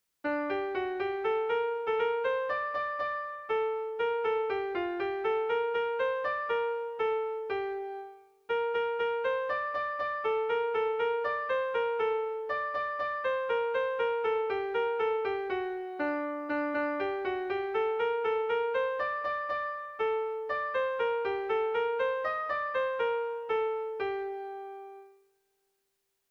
Melodías de bertsos - Ver ficha   Más información sobre esta sección
Irrizkoa
Hamabiko txikia (hg) / Sei puntuko txikia (ip)
ABDEAF